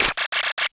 m41a-dryfire.wav